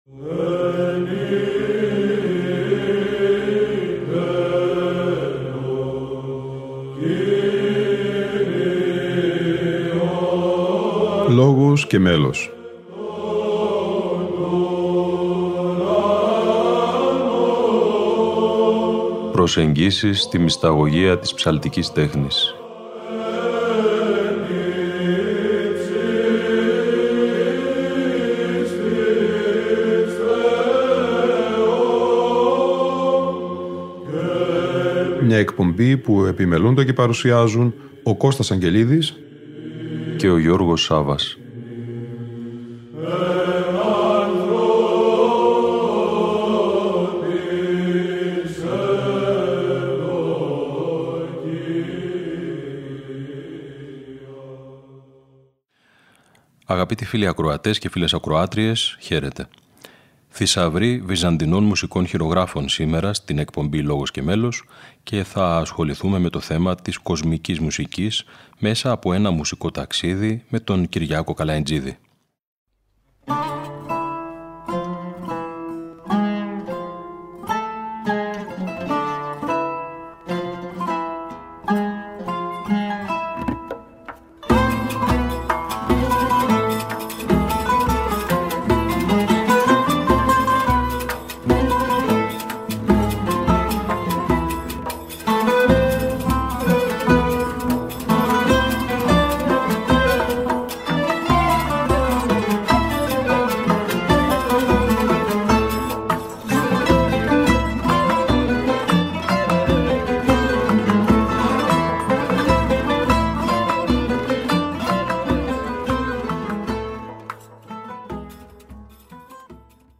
Μία συνομιλία